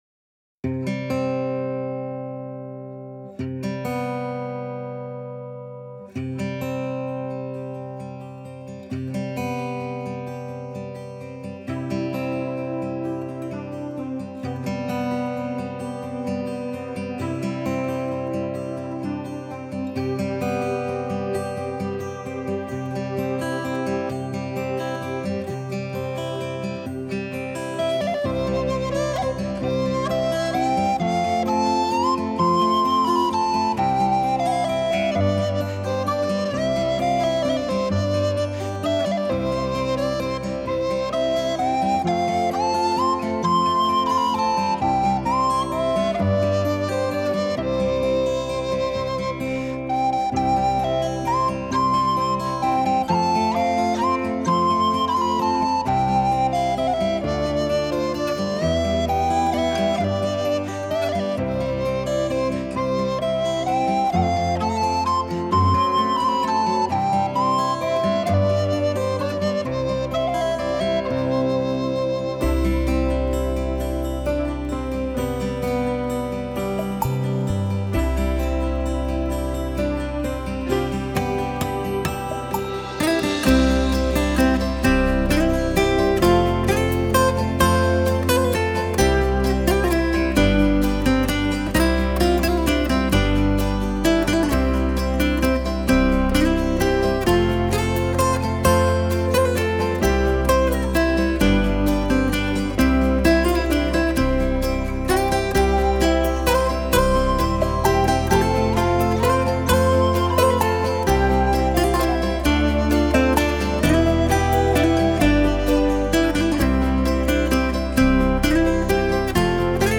空灵飘渺的音乐世界；成军20年来的唯一精选；
大自然音乐的代名词